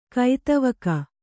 ♪ kaitavaka